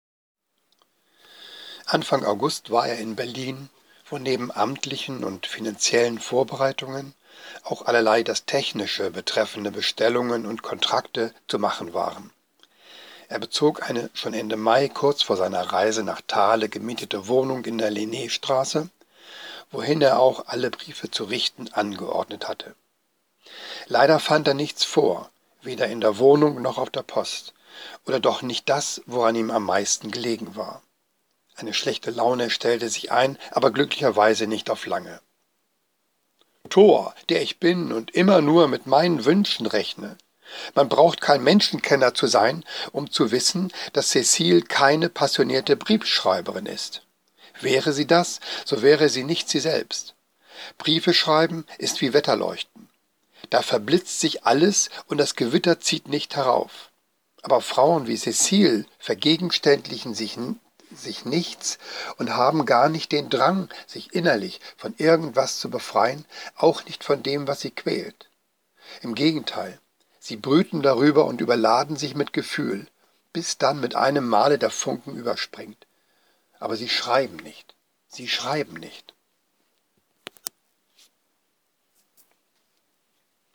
Zwei kleine Leseproben, mehr oder weniger zufällig ausgewählt.
Gelesen leider mit verschnupfter Stimme.